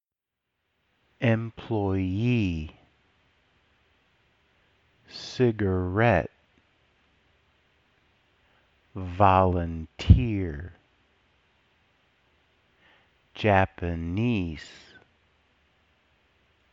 1. Stress the suffix for words ending in: -ee, -ette, eer, -ese.
• employEE
• cigarETTE
• volunTEER
• JapanESE